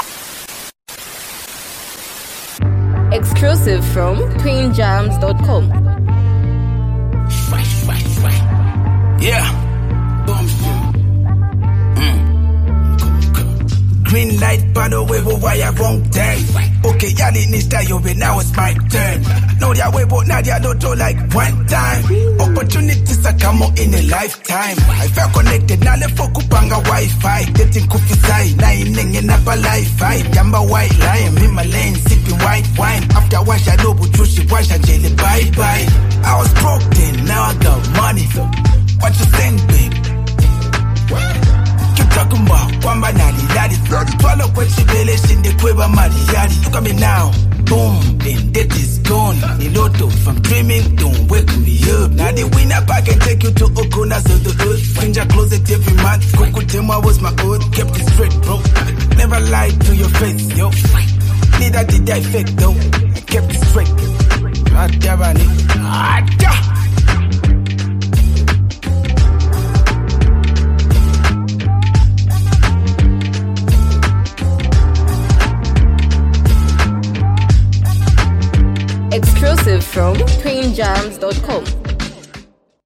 powerful hip-hop beat